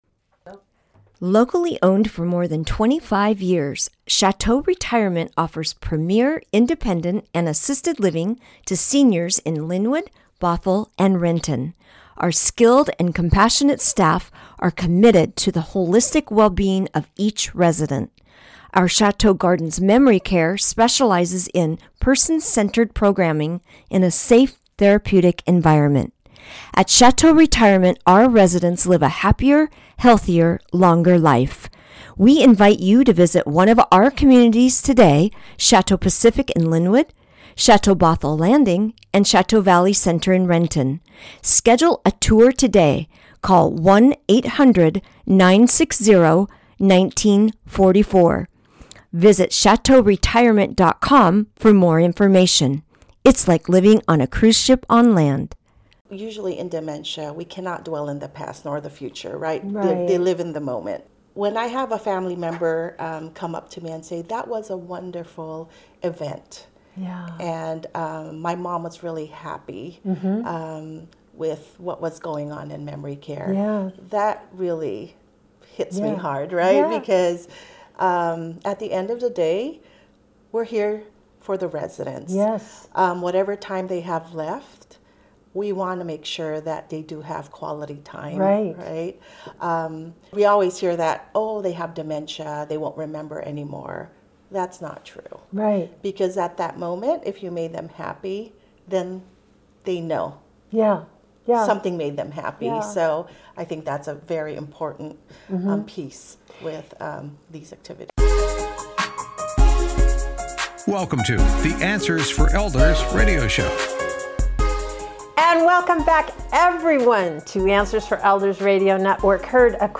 This episode was recorded at Chateau Valley Center in Renton, Washington.